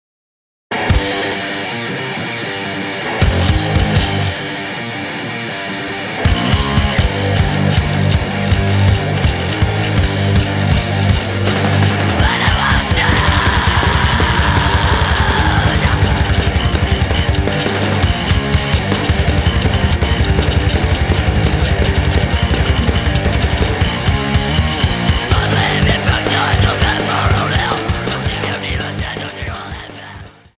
An hour of Finnish Devil Metal Made In Hell!!!